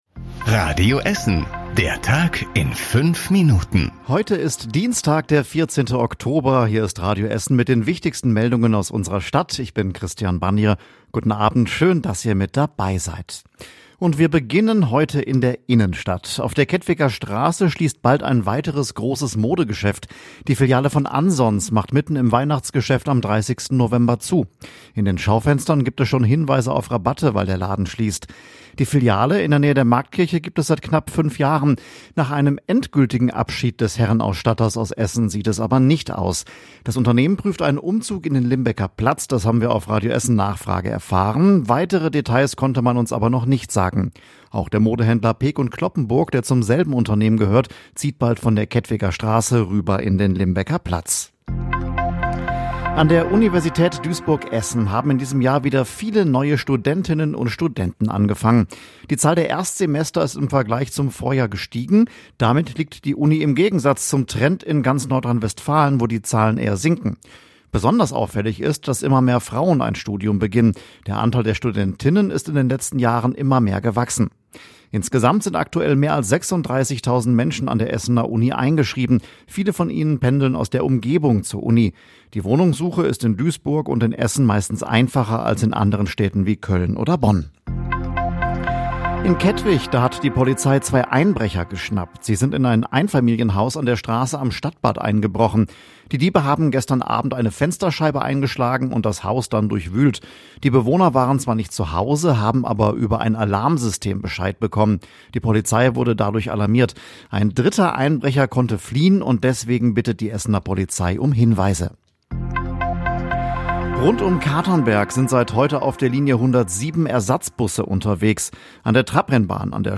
Die wichtigsten Nachrichten des Tages in der Zusammenfassung